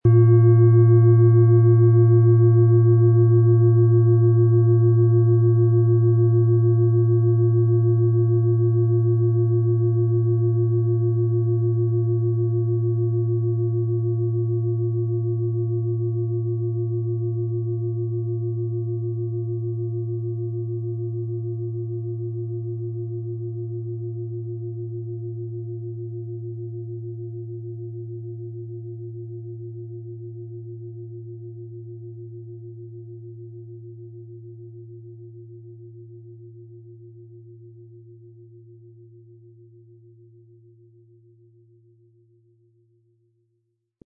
Planetenschale® Nach oben öffnend & Geborgen fühlen mit Platonisches Jahr & Mond, Ø 26 cm, 1900-2000 Gramm inkl. Klöppel
Von erfahrenen Meisterhänden in Handarbeit getriebene Klangschale.
• Einsatzbereich: Über dem Kopf sehr intensiv spürbar. Ein unpersönlicher Ton.
• Tiefster Ton: Mond
PlanetentönePlatonisches Jahr & Mond
MaterialBronze